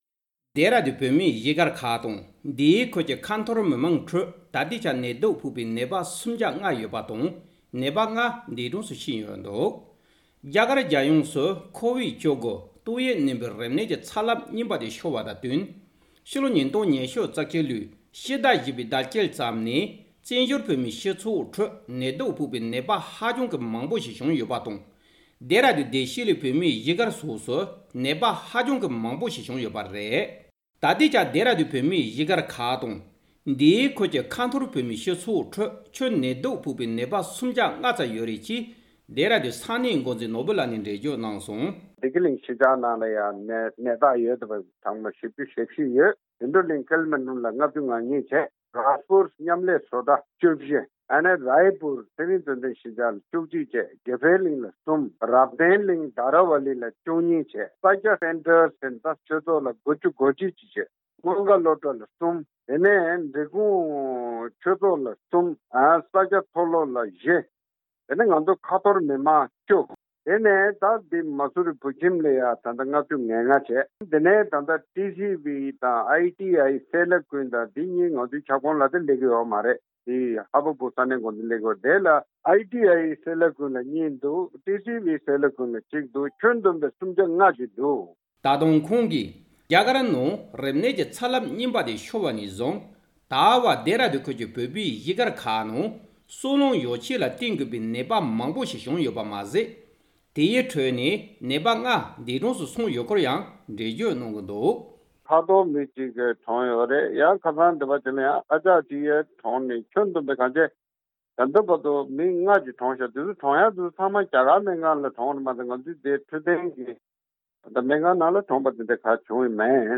ས་གནས་ནས་བཏང་བའི་གནས་ཚུལ་ལ་གསན་རོགས་གནང་།